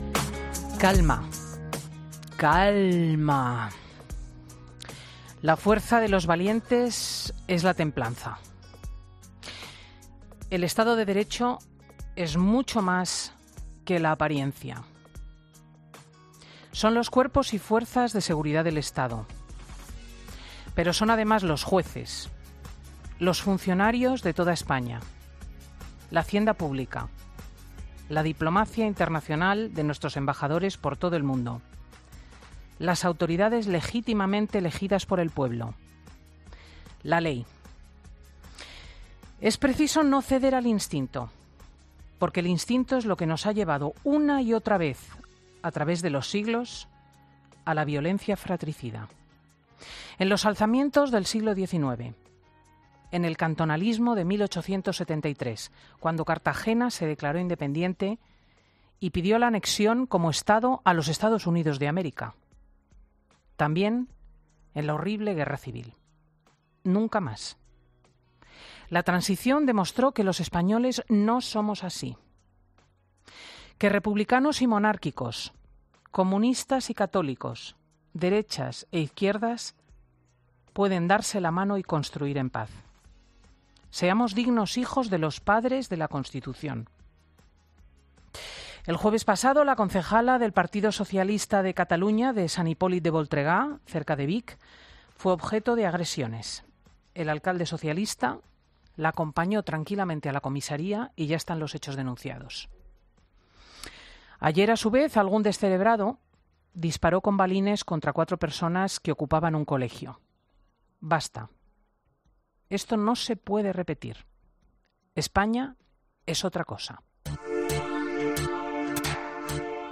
Monólogo de Cristina López Schlichting
Editorial de este sábado en 'Fin de Semana'